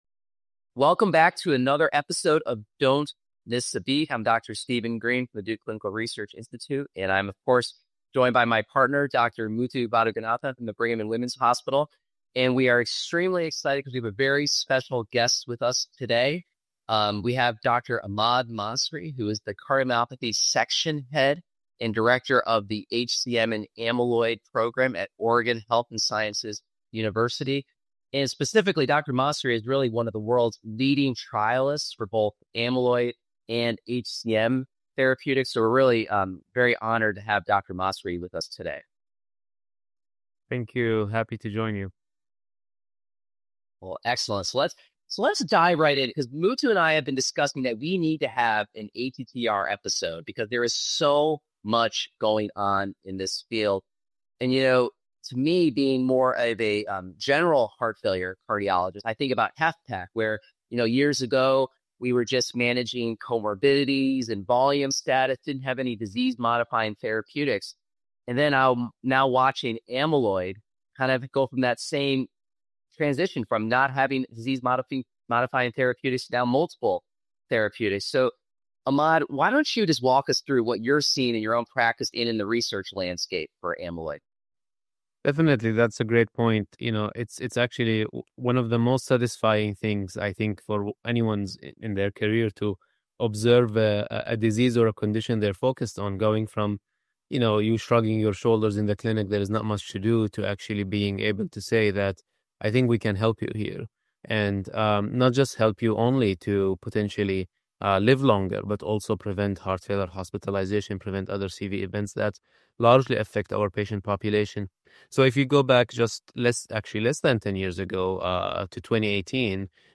The conversation explores whether stabilizers and silencers can or should be combined